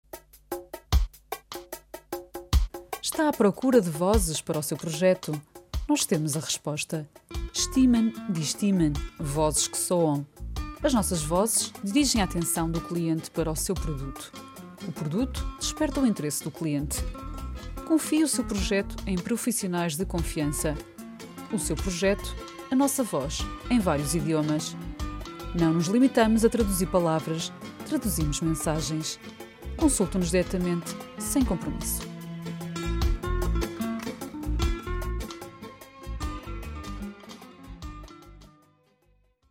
Portuguese native speaker and voice over talent.
Sprechprobe: Werbung (Muttersprache):